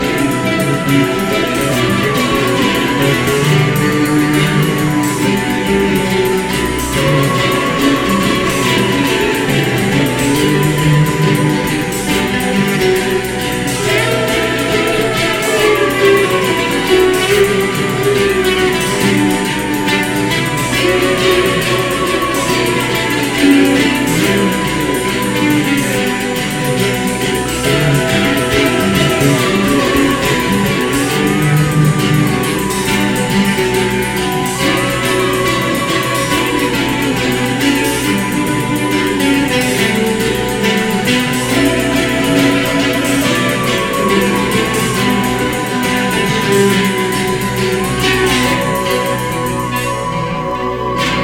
インダストリアルな金属を打ち鳴らすポストパンク流儀のアヴァン・チューン
エキゾチックな実験フォーク